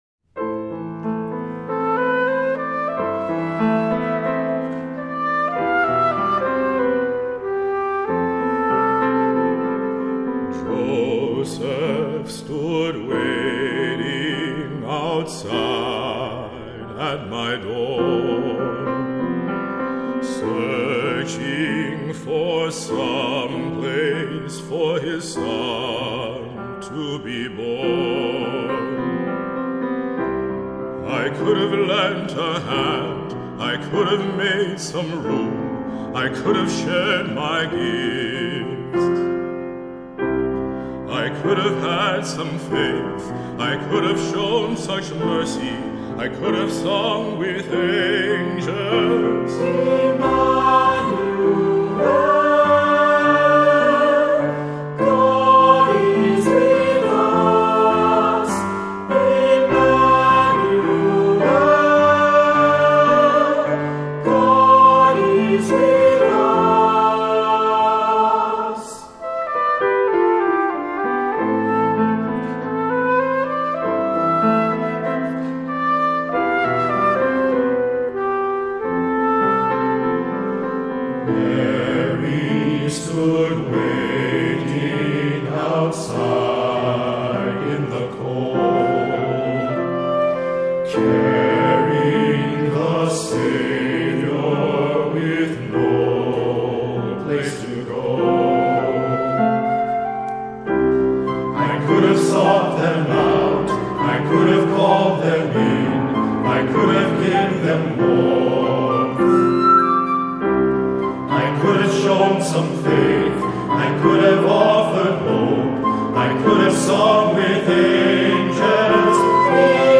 Voicing: Three-part equal; solo